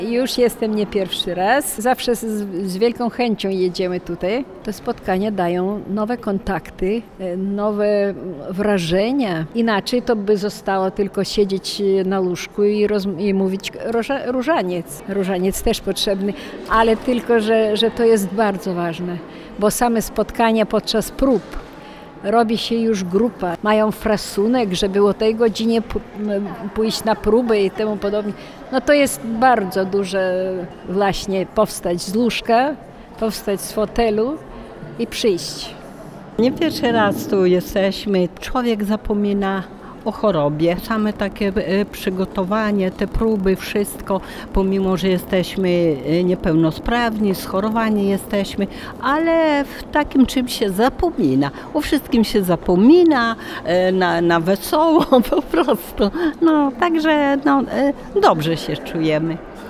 – To dla nas świetna zabawa oraz okazja do tego, aby poznać nowych ludzi – zgodnie twierdzą uczestnicy animacji teatralnych.